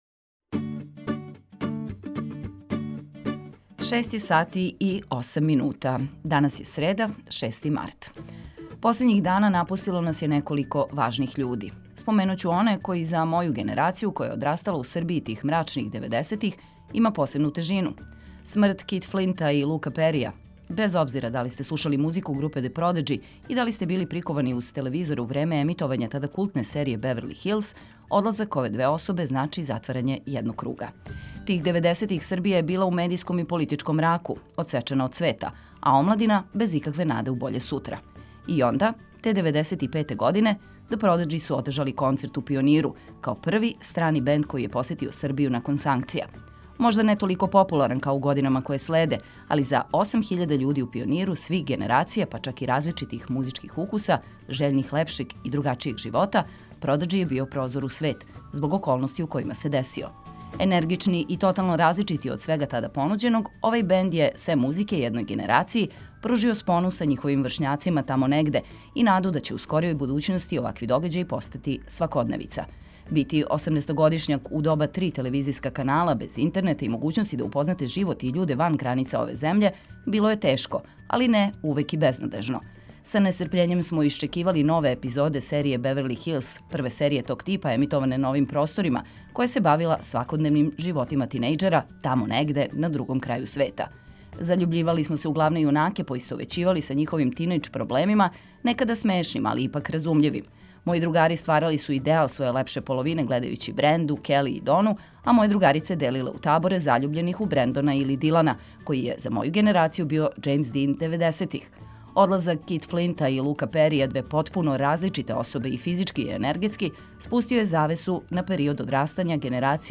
Крећемо у нови дан лепо расположени, праћени ведром музиком и важним информацијама.